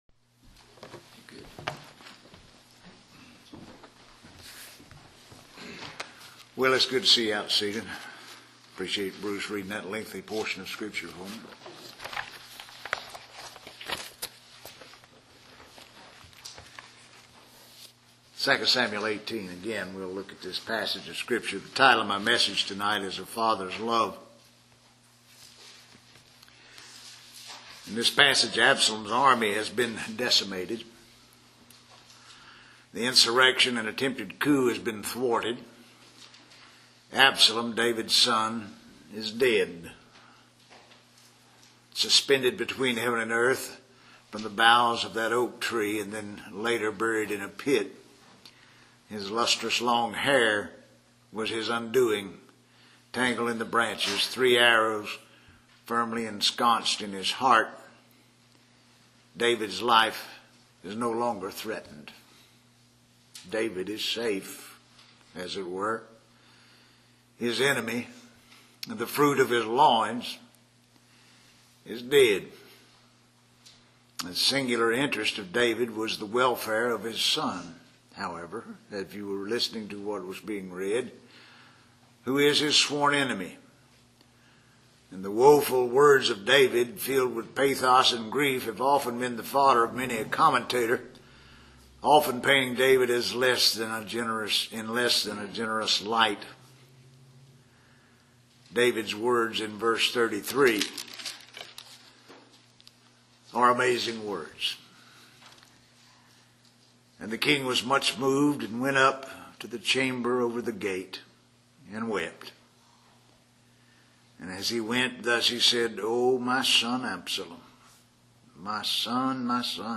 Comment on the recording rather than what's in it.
Currently casting to Device Name A Father's Love (Bible Conference, Sovereign Grace Church, Jackson, Mo.)